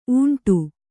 ♪ ūṇṭu